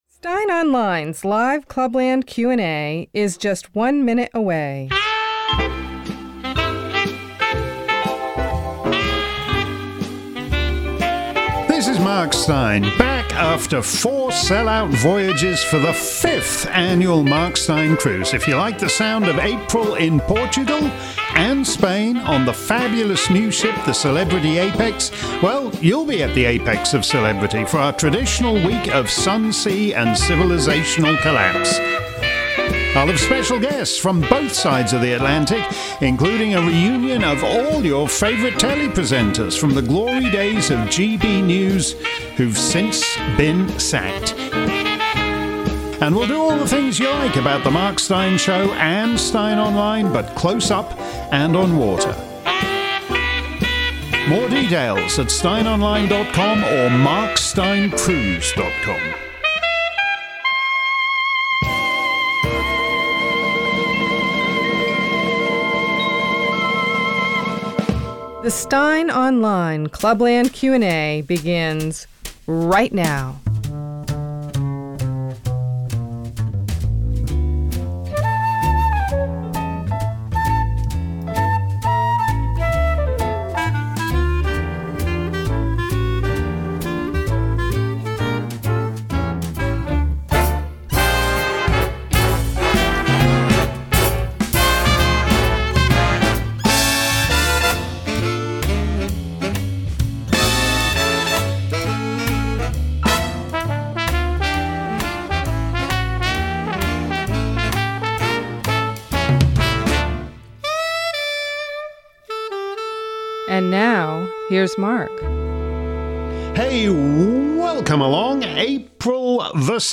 If you missed today's edition of Steyn's Clubland Q&A live around the planet, here's the action replay. This week's show covered a range of questions from corruption in America to civil war in Europe.